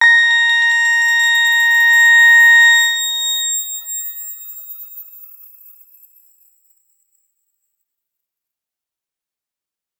X_Grain-A#5-ff.wav